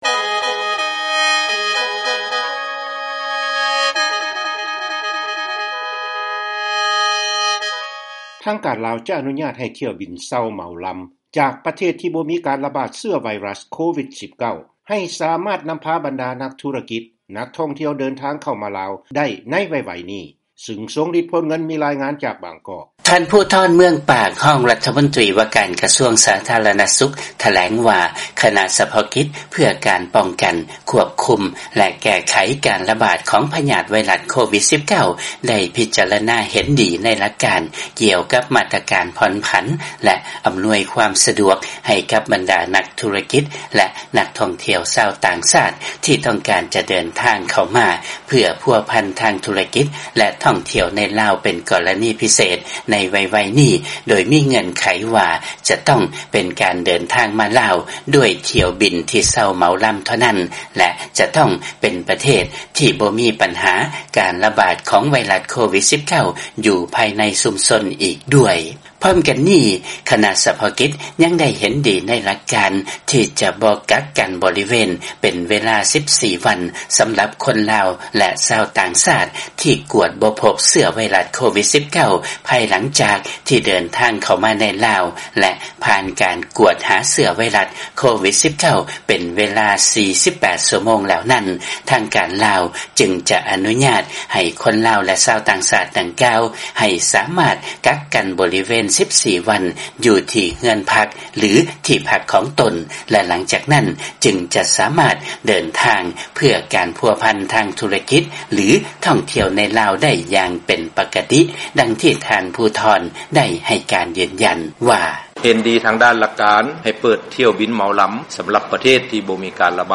ເຊີນຟັງລາຍງານ ທາງການລາວ ຈະອະນຸຍາດໃຫ້ຖ້ຽວບິນເຊົ່າເໝົາລຳ ຈາກປະເທດທີ່ບໍ່ມີການລະບາດຂອງເຊື້ອໄວຣັສ ໂຄວິດ-19 ເຂົ້າມາໃນປະເທດໄດ້